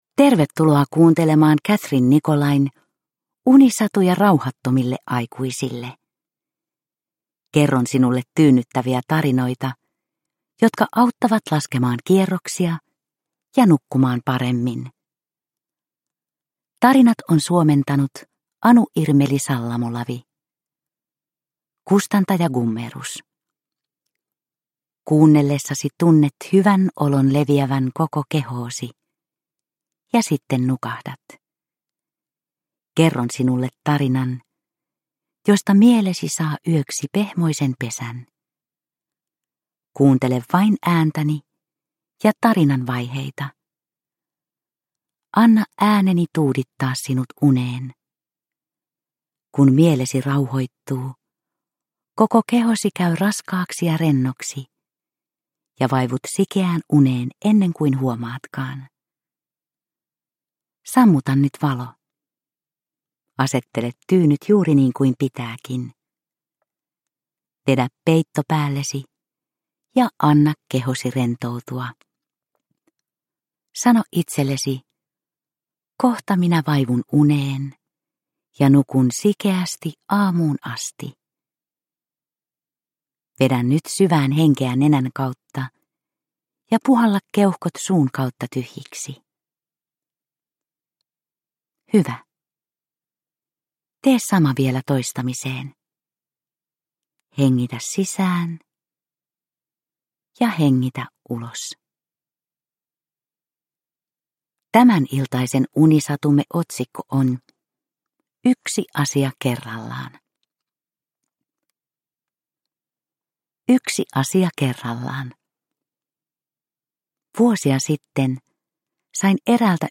Unisatuja rauhattomille aikuisille 26 - Yksi asia kerrallaan – Ljudbok – Laddas ner